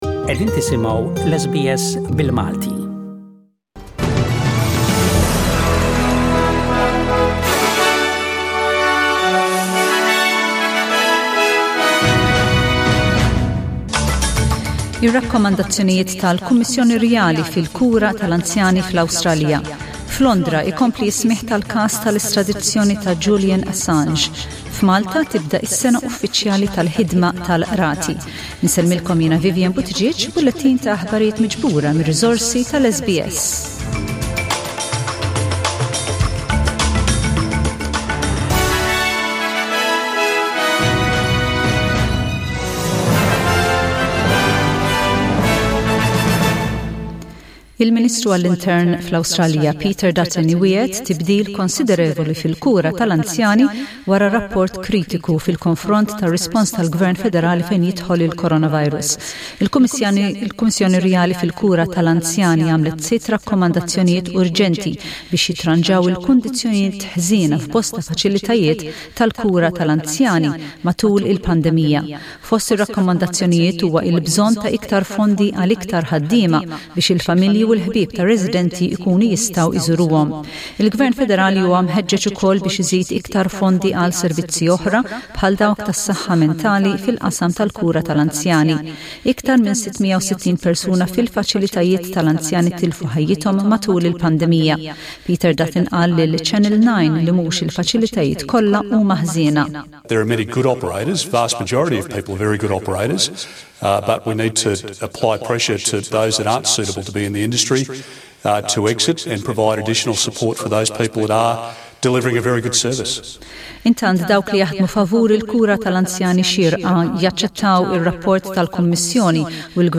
SBS Radio | News in Maltese: 2/10/2020